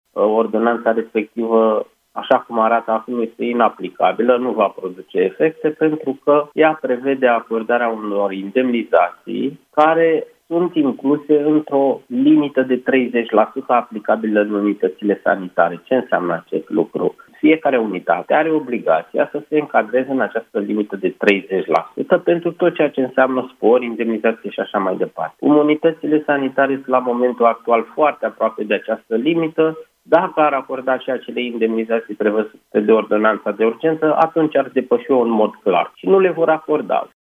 cu explicații la Europa fm.